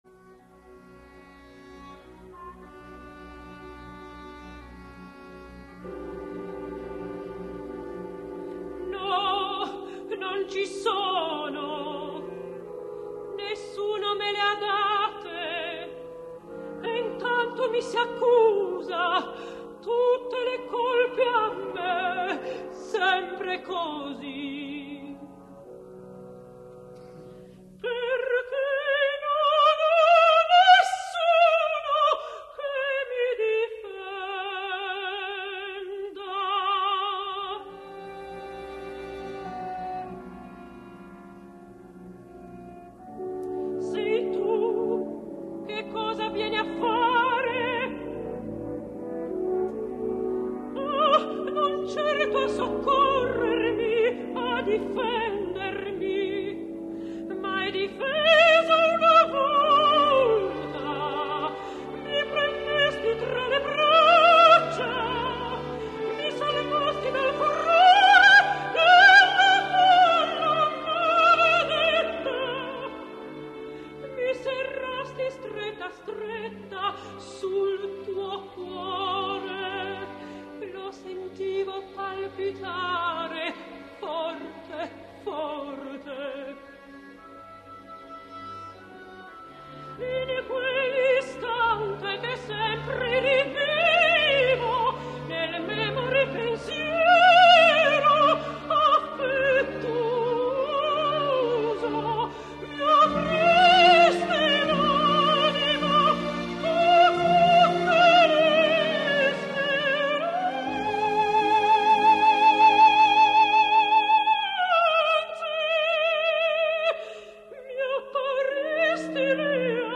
Revolutionsoper — {historischer Verismo}
Mariella [Sopran]